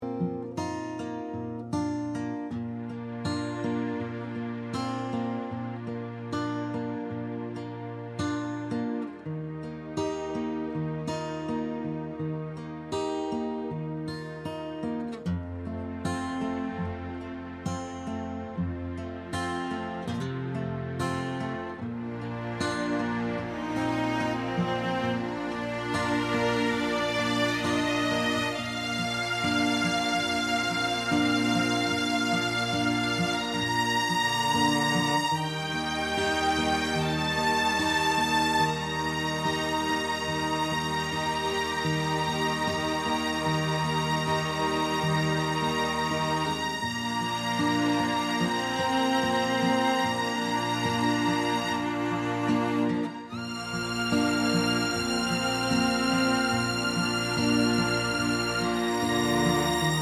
Live with Play Off Pop (1970s)